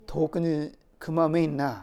Aizu Dialect Database
Type: Statement
Final intonation: Falling
Location: Showamura/昭和村
Sex: Male